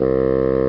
Bassoon Sound Effect
Download a high-quality bassoon sound effect.
bassoon.mp3